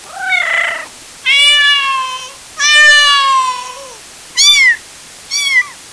Dans cette expérience, le rapport signal sur chat de chaque lettre peut atteindre jusqu’à -45dB!
Comme je ne voulais pas que le fichier audio dure trop longtemps, j’ai superposé les 22 caractères ASCII (8 bits) du flag les uns par dessus les autres, chacun avec son propre code de Gold.
Je n’ai pas voulu compliquer les choses : le premier échantillon du son contient le premier bit de la séquence de Gold, et ainsi de suite (un bit de séquence par échantillon sonore).
Espérons que les chats n’ont pas fait trop de bruit !